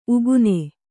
♪ ugune